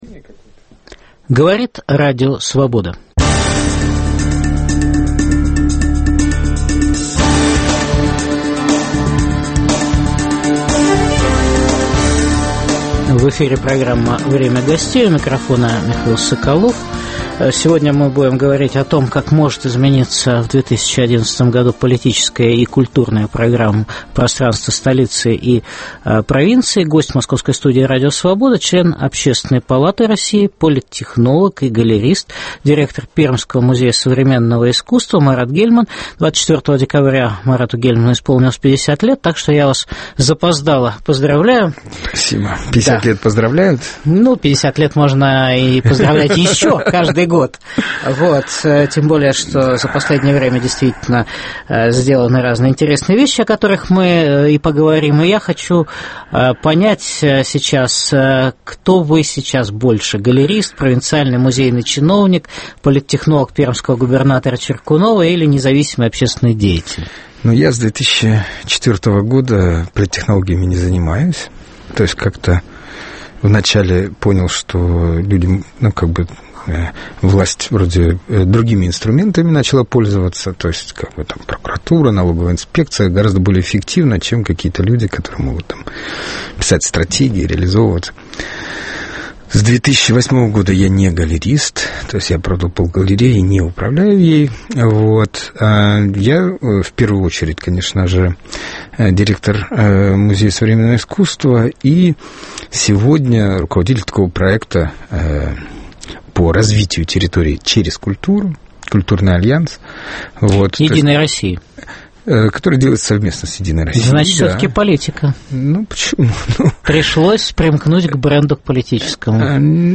Как изменится в 2011 году политическое пространство столицы и провинции? Гость московской студии Радио Свобода политтехнолог и галерист, директор Пермского музея современного искусства Марат Гельман.